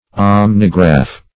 Omnigraph \Om"ni*graph\, n.